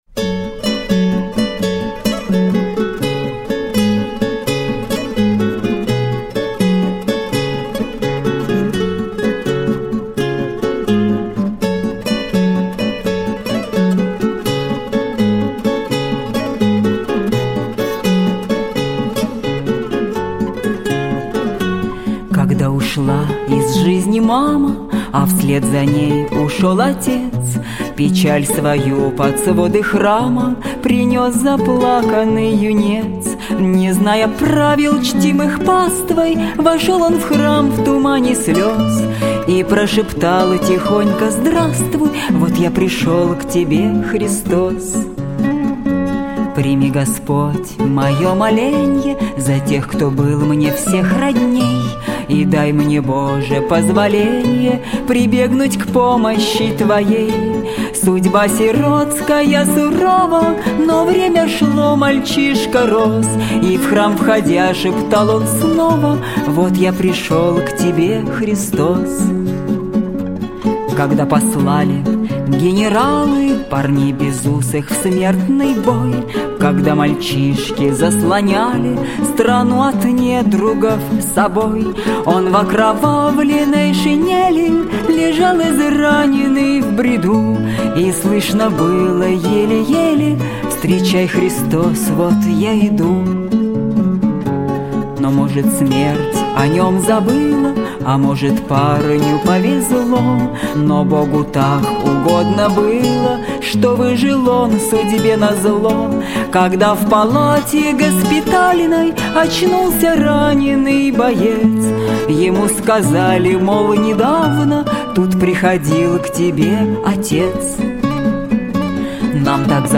гитара.